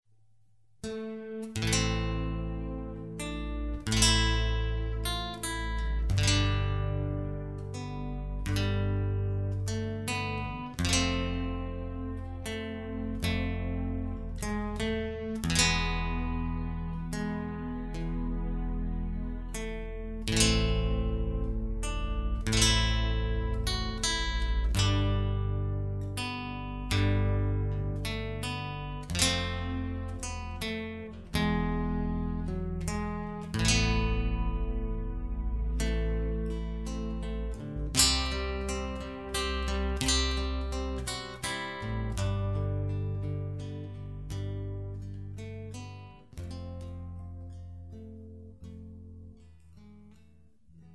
Gitarrist
Greensleeves (Irish)